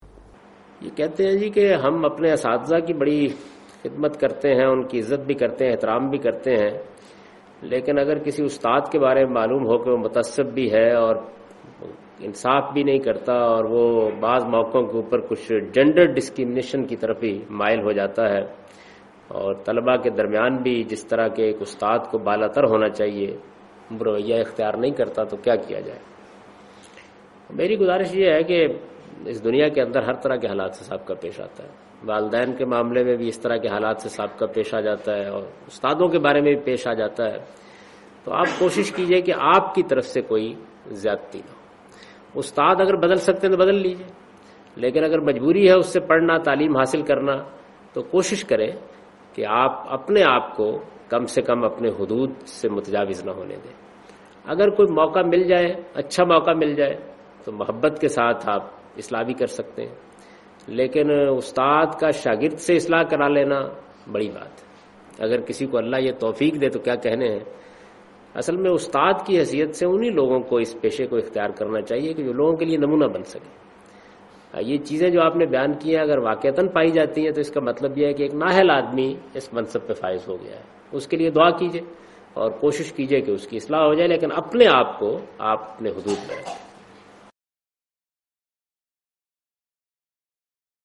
Category: English Subtitled / Questions_Answers /
Javed Ahmad Ghamidi responds to the questionl 'Should we be respectful to teachers despite their being morally flawed'?